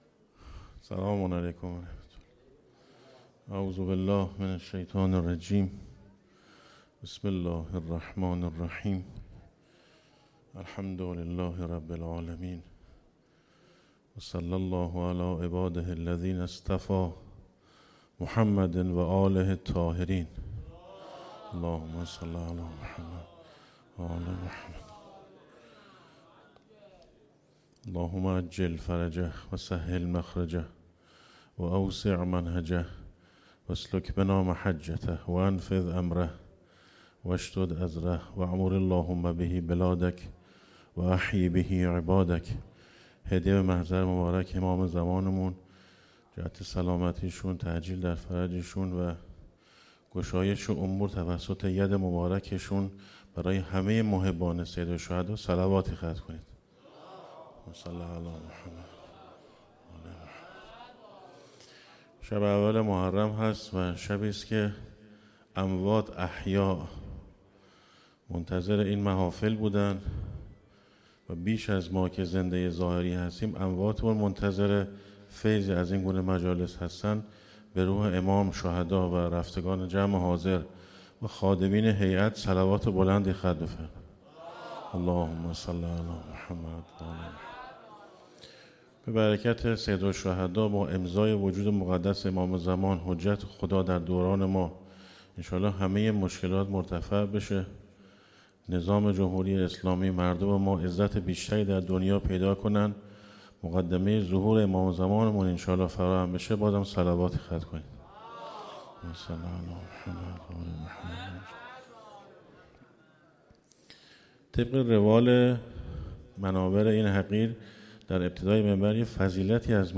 گزارش صوتی شب اول محرم الحرام 1445 ه.ق